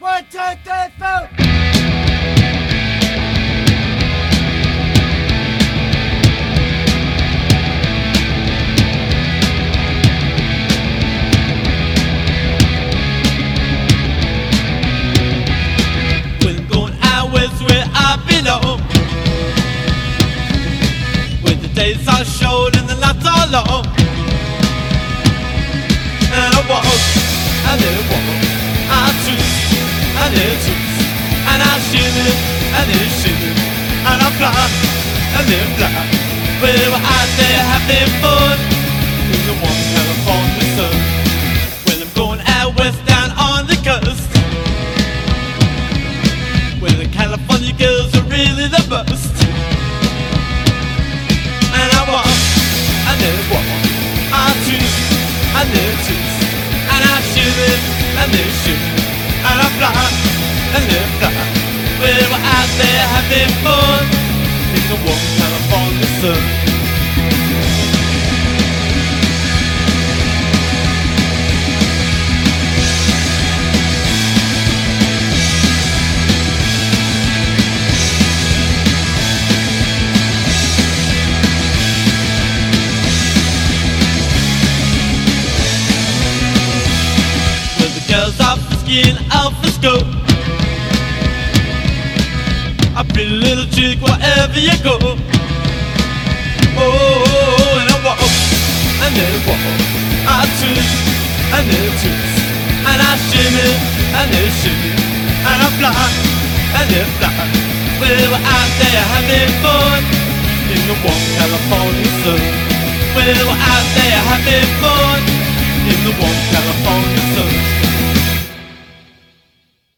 BPM181-194
surf rock classic